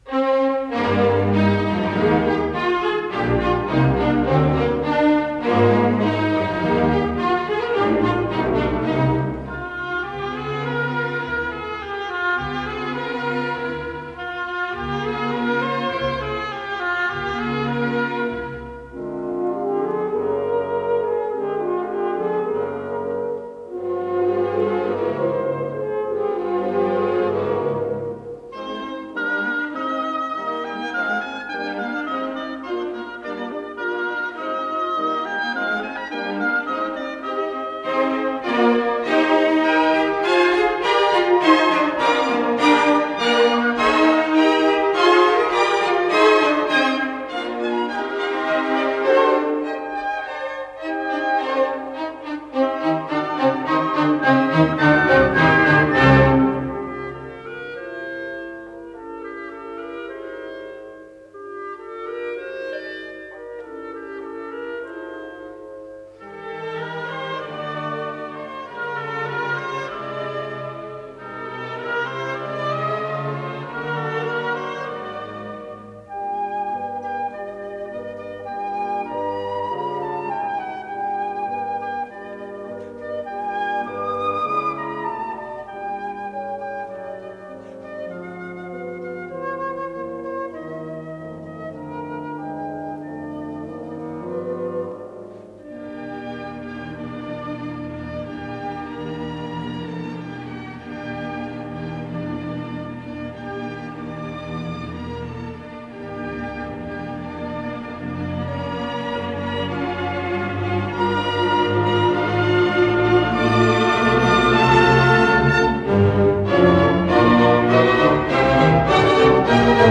с профессиональной магнитной ленты
ПодзаголовокИз цикла "Деревенские концерты" ("Развлечения") для малого оркестра, соль мажор, соч. 32 №1
Аллегро маркато
ИсполнителиАнсамбль солистов Государственного академического оркестра СССР
Дирижёр - Владимир Вербицкий
ВариантДубль моно